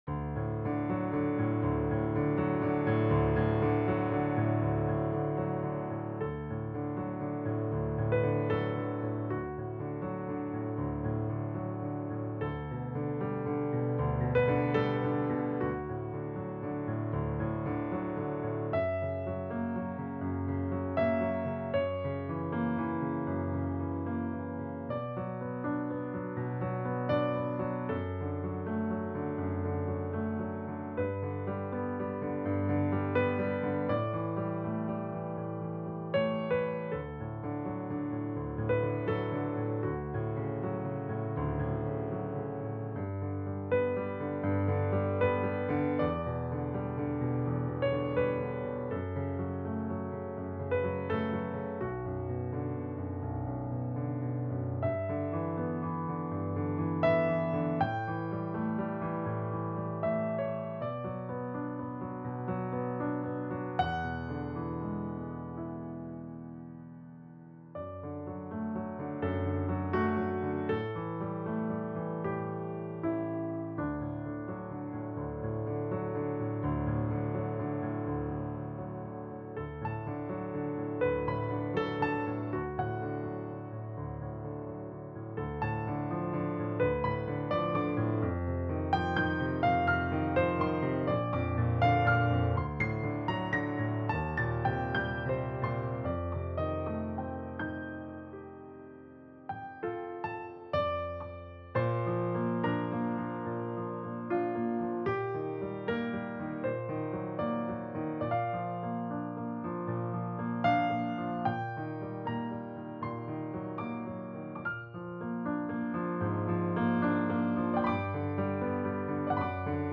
Intermediate-Advanced piano solo
Voicing/Instrumentation: Piano Solo